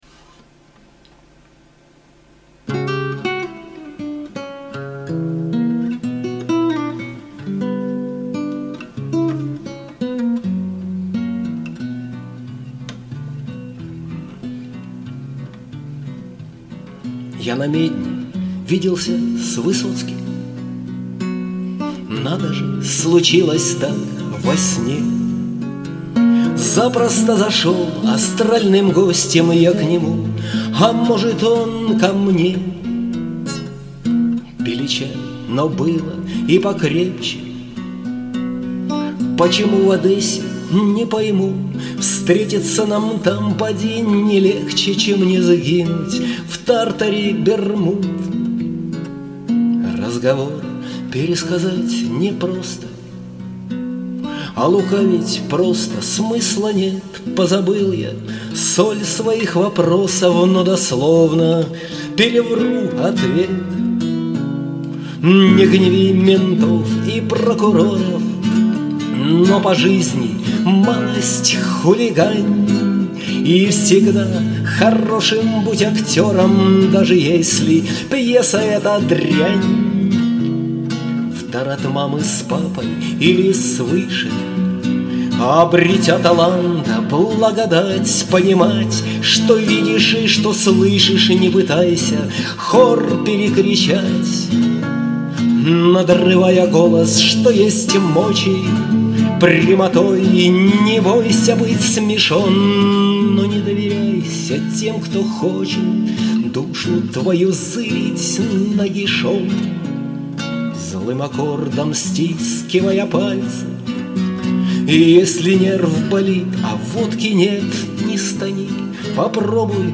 • Жанр: Авторская песня
мистическая лирическая